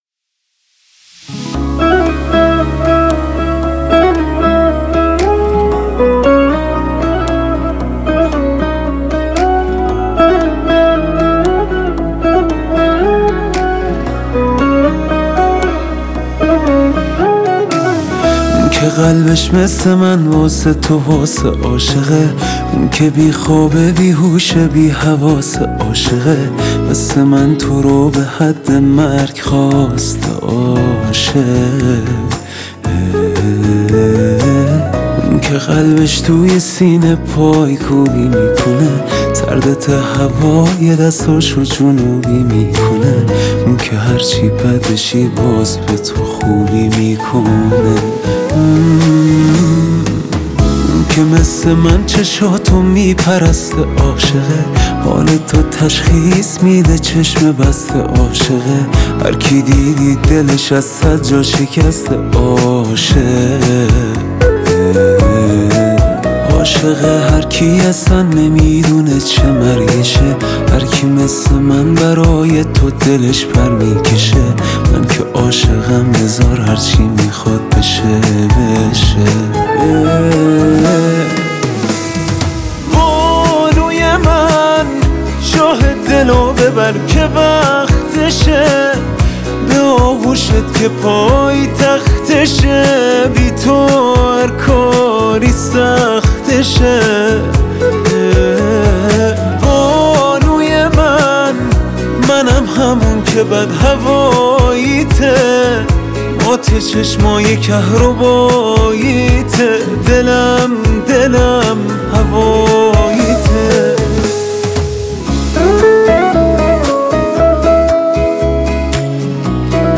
دسته بندی آهنگ عاشقانه. ۲۷ مهر ۱۴۰۰.